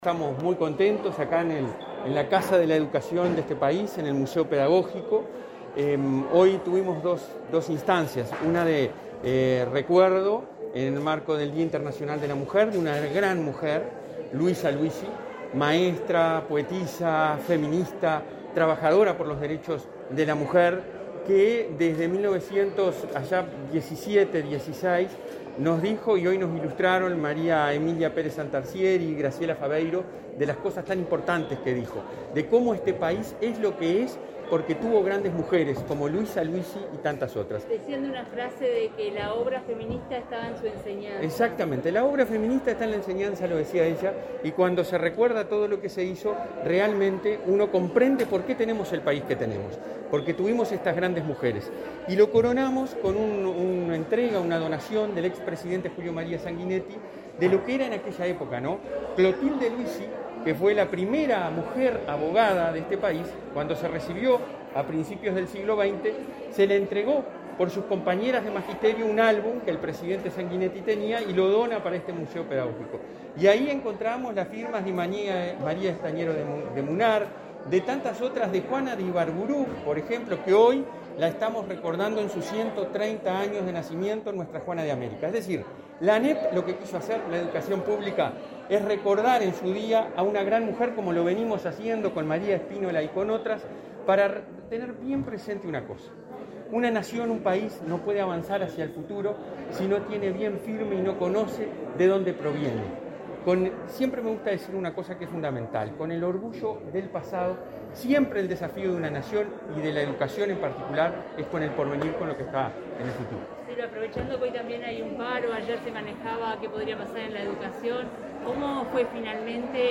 Declaraciones del presidente de ANEP a la prensa
El presidente de la institución, Robert Silva, en declaraciones a la prensa, destacó su trayectoria.